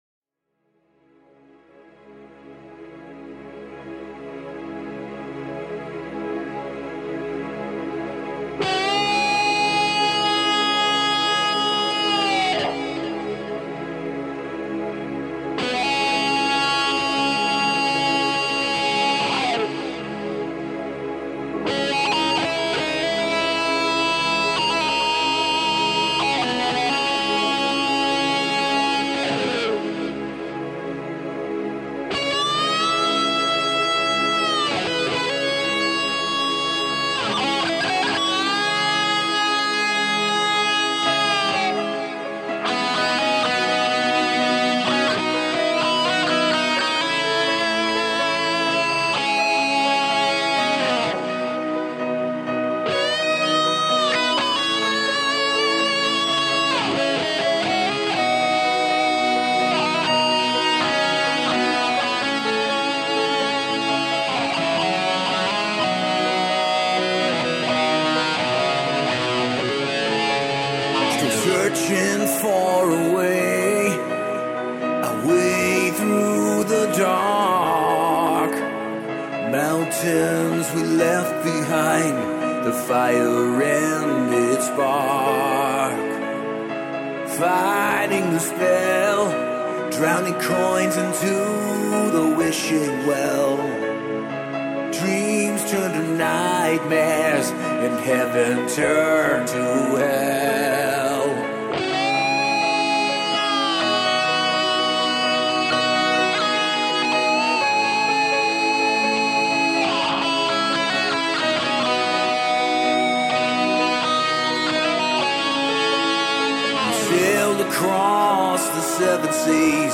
Жанр: classicmetal